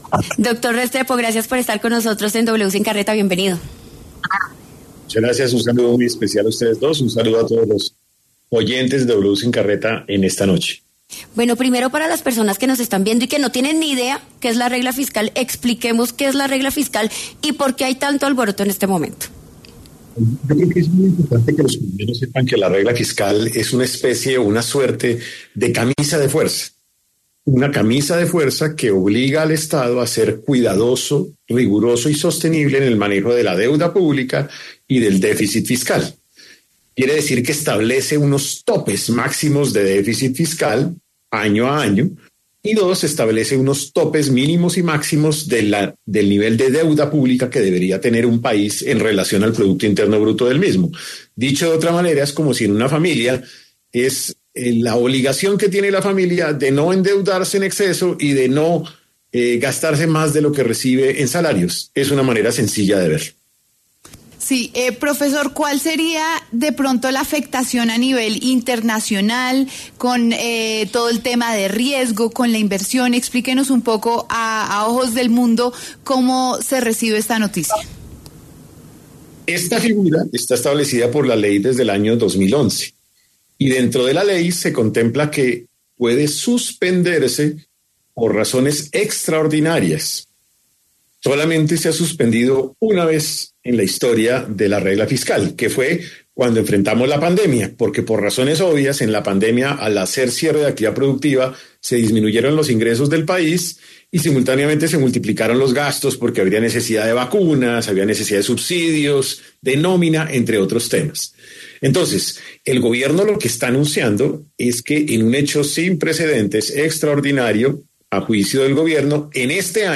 El exministro José Manuel Restrepo conversó con W Sin Carreta a propósito de la decisión del Gobierno Nacional de estudiar la suspensión de la regla fiscal con una cláusula de escape.
El exministro de Hacienda José Manuel Restrepo pasó por los micrófonos de W Sin Carreta para hablar sobre la importancia de la regla fiscal.